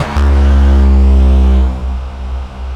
Bass 1 Shots (106).wav